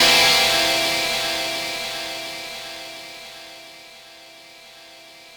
ChordC7.wav